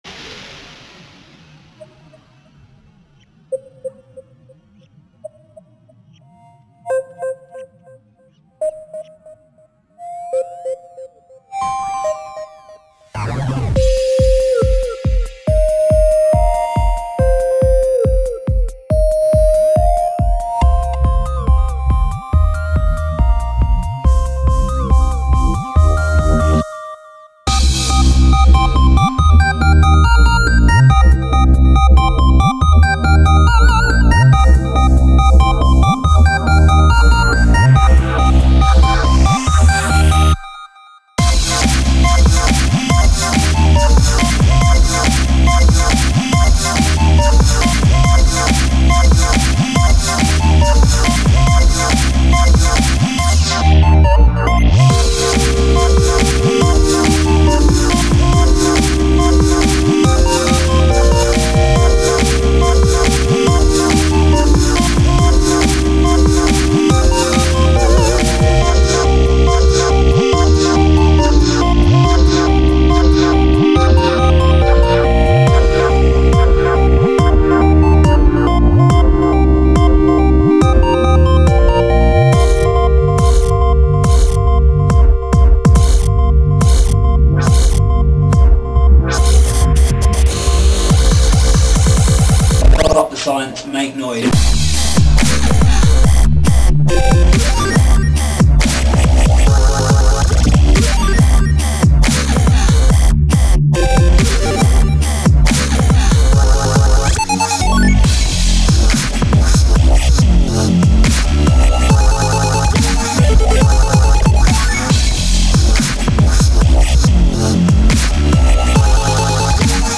Rescue Mission [Dubstep
dubstep chiptune chipstep chipdance edm dance
nice chillin music